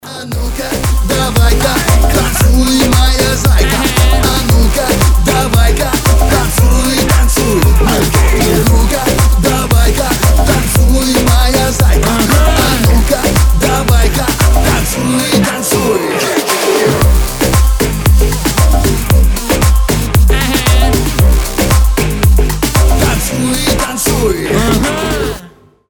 • Качество: 320, Stereo
позитивные
веселые
заводные
смешные